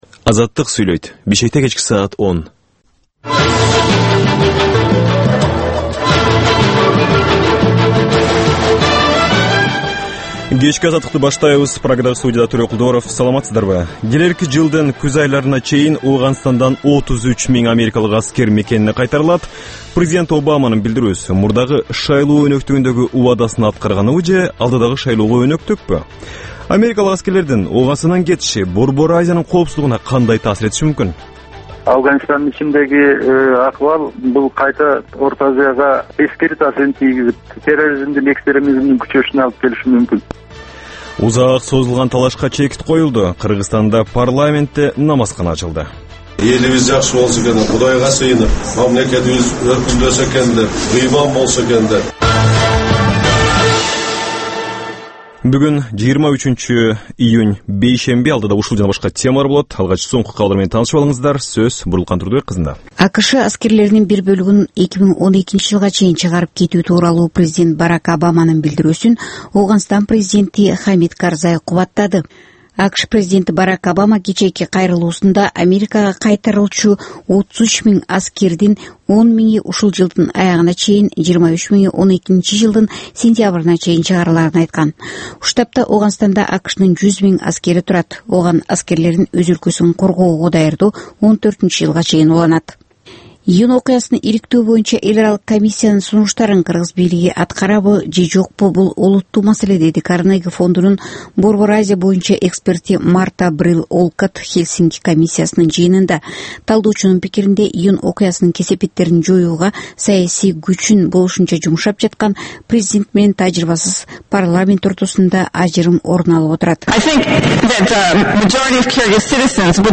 Кечки 10догу кабарлар